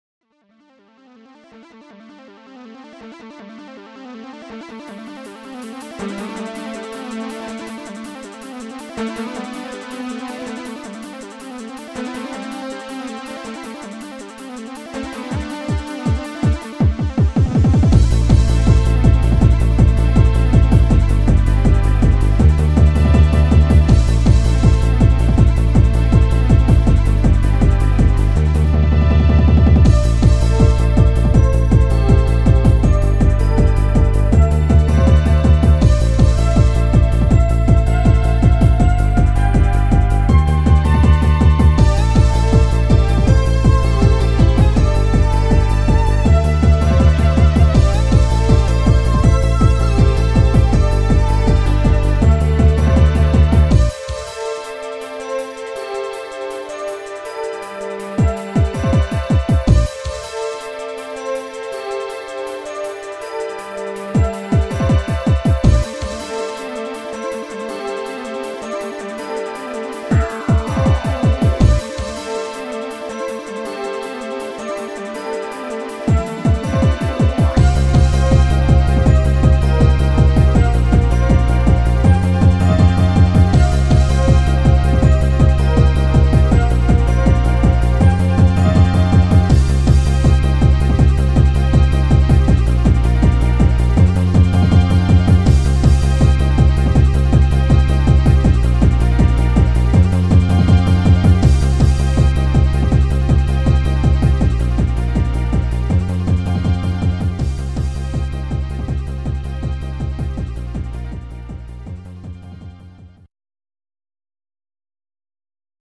[ Original Format : Scream Tracker III
Synth.Lead
Kick.Bassdrum
Hihat
Open.Hihat
Bassdrum.+.Crash.Cymbal
Rev.Cymbal
Thrum.Bass
x3.strings.minor
Fantasia.Synth
fx.enginesound